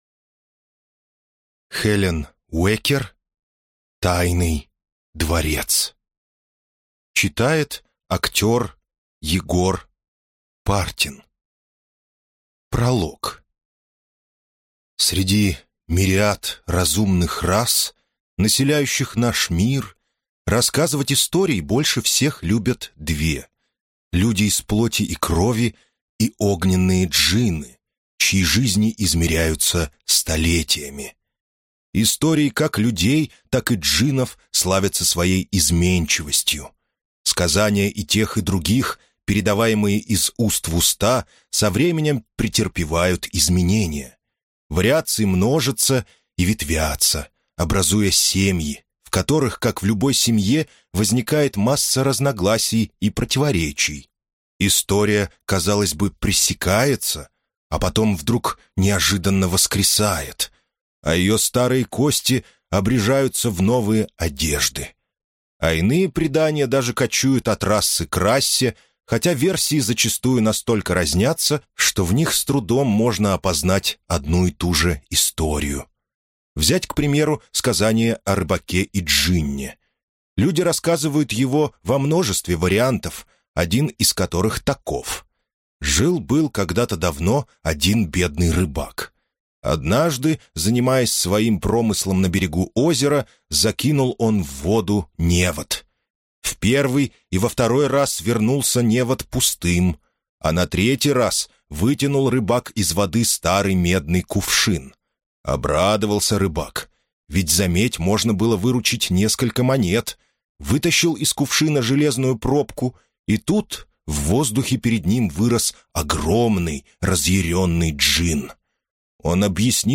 Аудиокнига Тайный дворец: Роман о големе и джинне | Библиотека аудиокниг